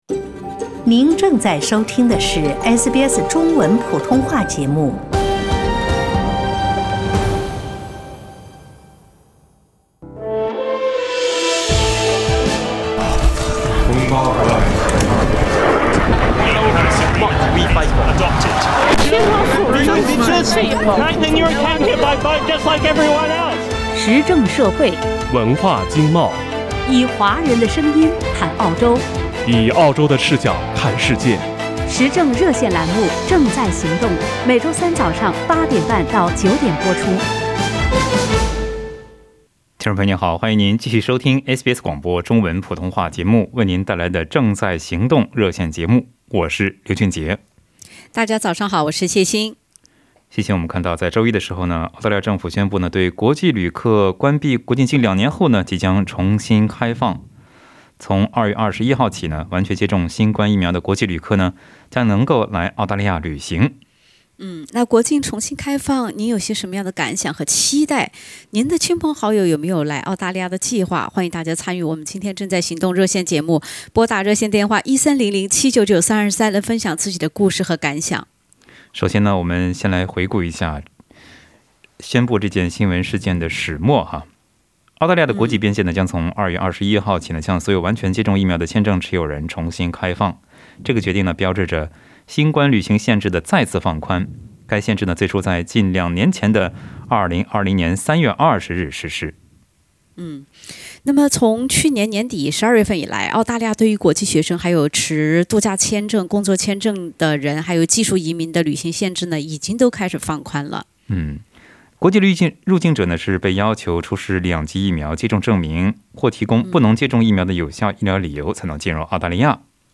请您点击收听本期《正在行动》热线节目的详细内容。